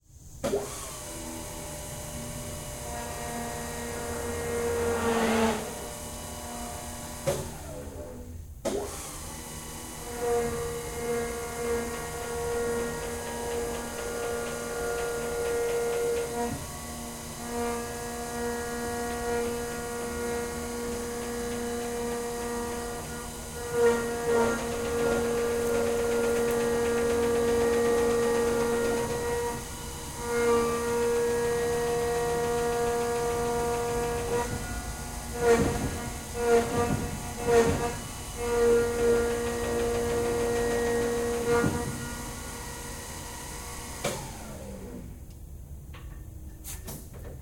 Pole Position - Chaffee M24 Light Tank